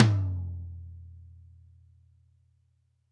Index of /90_sSampleCDs/ILIO - Double Platinum Drums 1/CD4/Partition E/GRETSCH TOMD